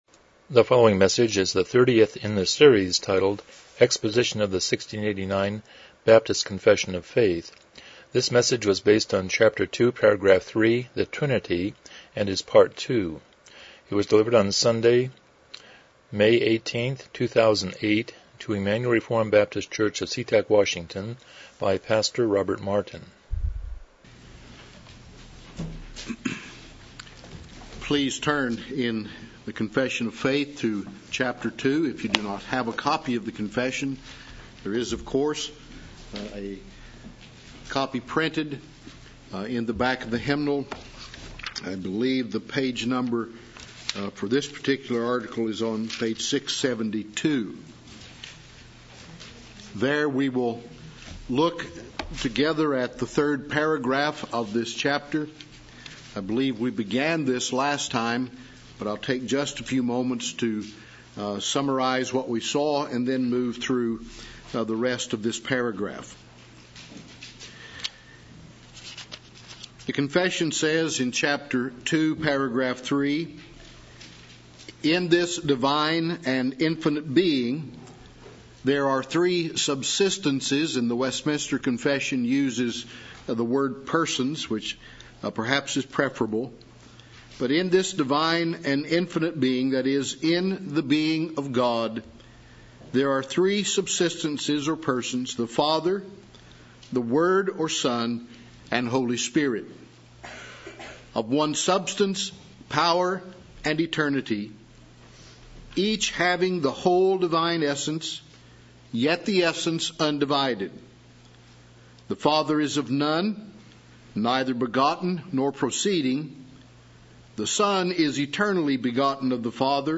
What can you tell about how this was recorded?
1689 Confession of Faith Service Type: Evening Worship « 45 Review #3